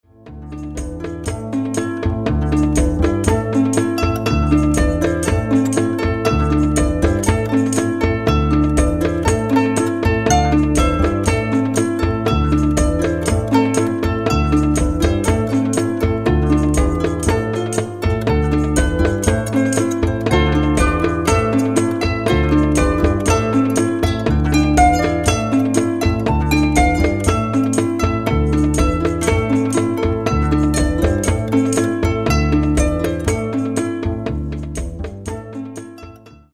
(Celtic harp, bass, percussions)  3'573.61 MB1.70 Eur